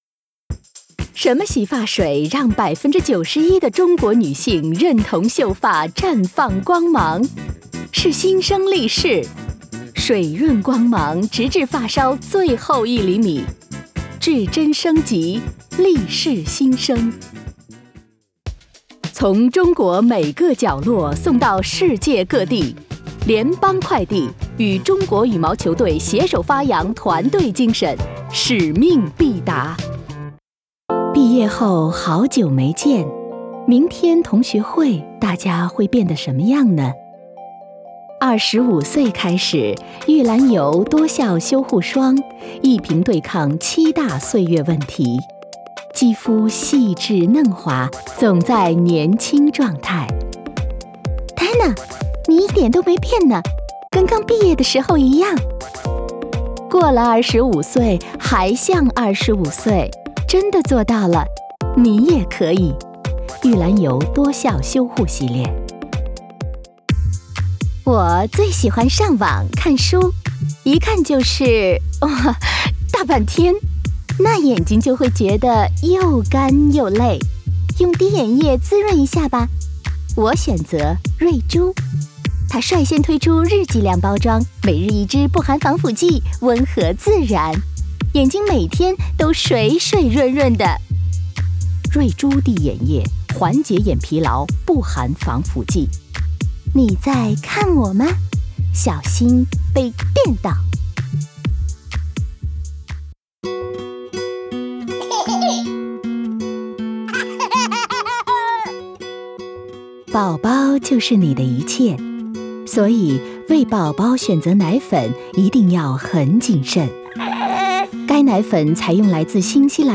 Chinese, Mandarin. Presenter, translator, warm, friendly, engaging.
Corporate and Narration Mandarin